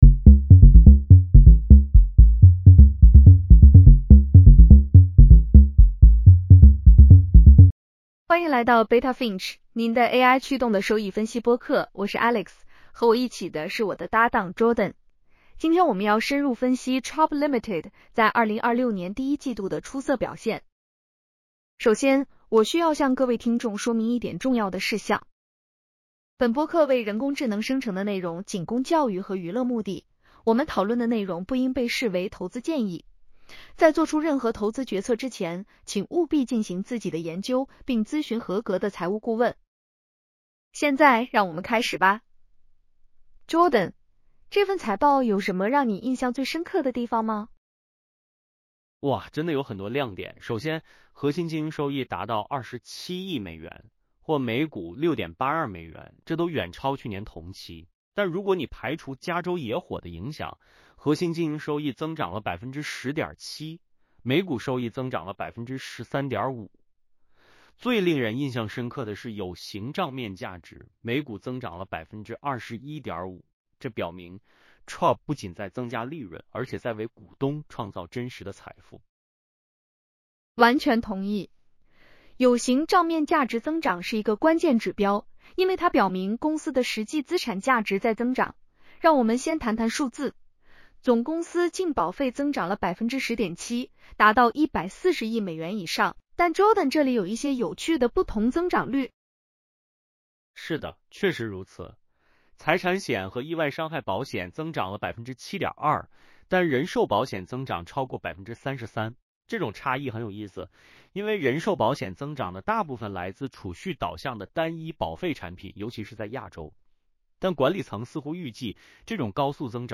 首先，我需要向各位听众说明一点重要的事项： 本播客为人工智能生成的内容，仅供教育和娱乐目的。